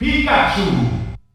The announcer saying Pikachu's name in German releases of Super Smash Bros.
Category:Pikachu (SSB) Category:Announcer calls (SSB) You cannot overwrite this file.
Pikachu_German_Announcer_SSB.wav